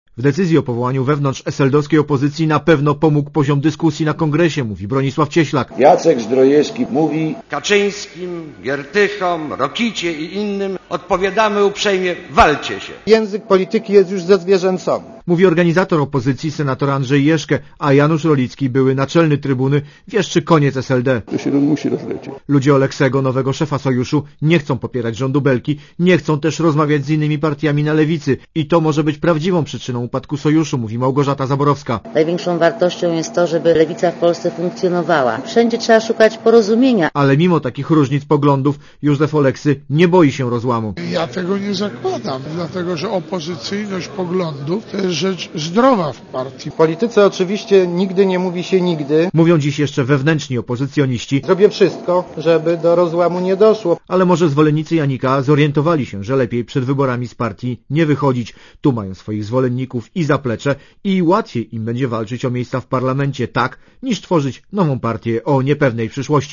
Korespondencja reportera Radia Zet